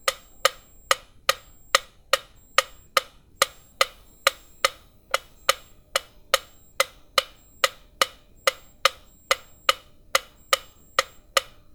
Tiempo presto en un metrónomo
metrónomo
rápido